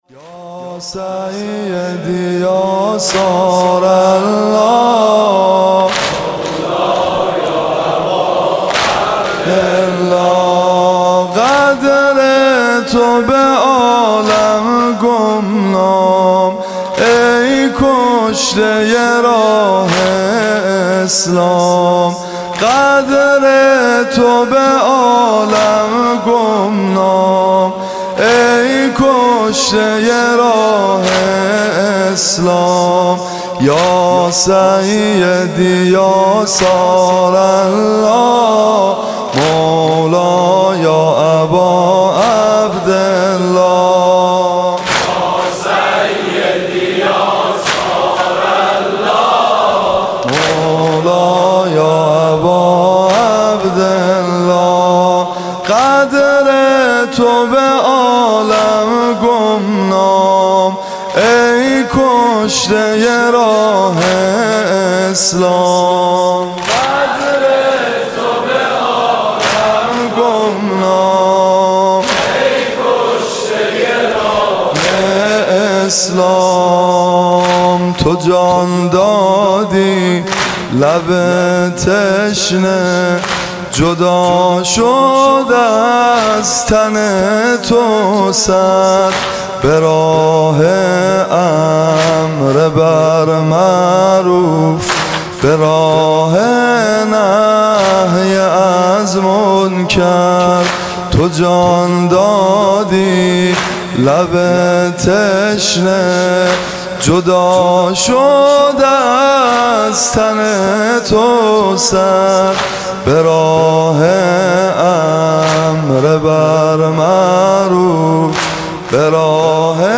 جلسه عزاداری شب هفتم محرم سال 1394
آه از کودک کشی و از ظلم حرمله ها (واحد)